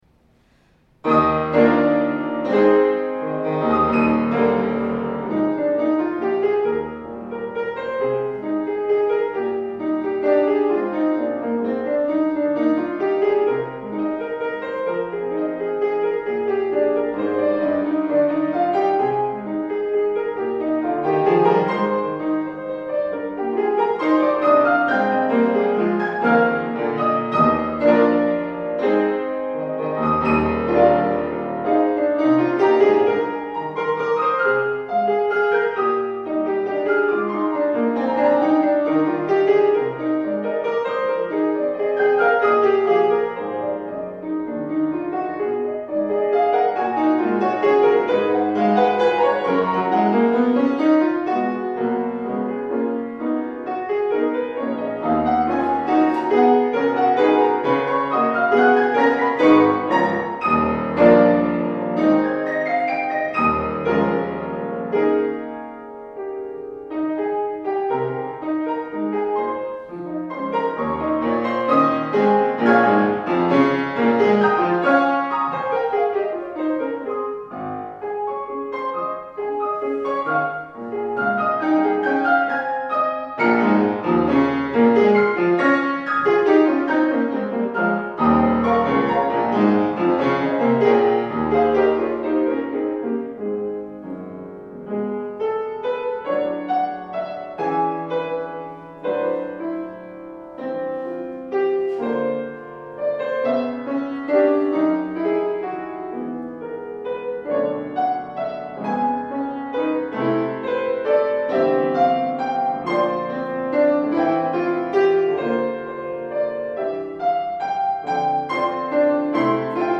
Piano duet–5′
A whimsical and celebratory duet.
piano